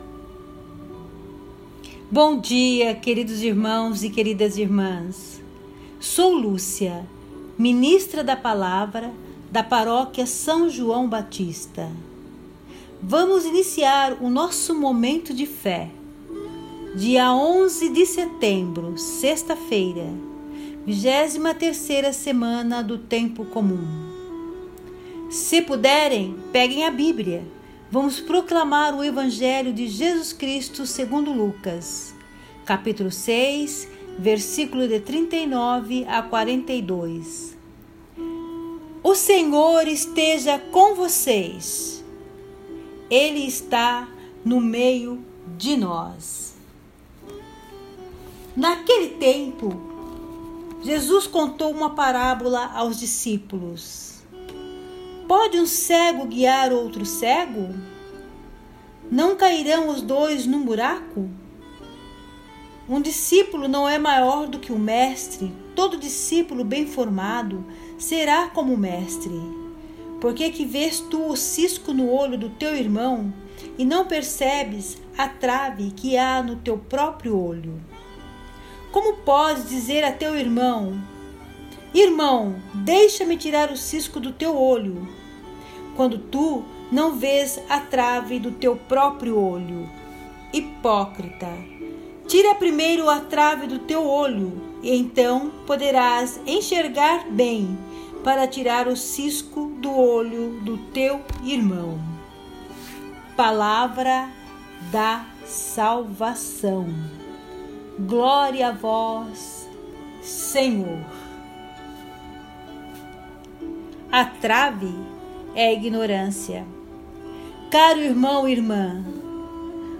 Evangelho do Dia